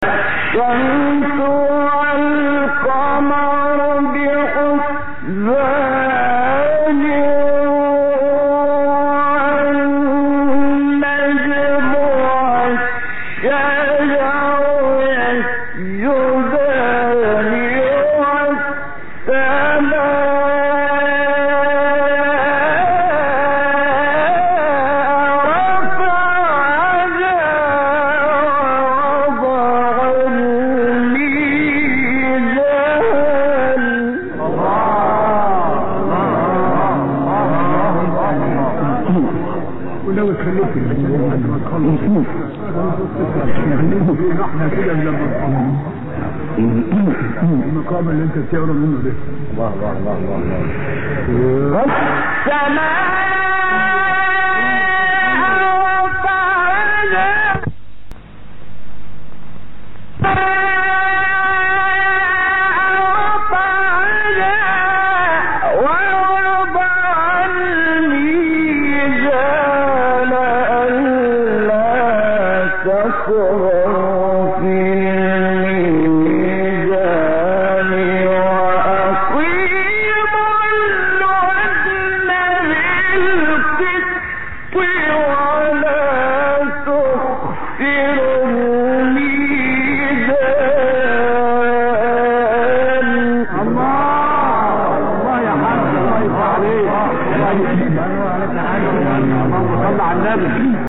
گروه فعالیت‌های قرآنی: فرازهای صوتی دلنشین با صوت قاریان برجسته مصری ارائه می‌شود.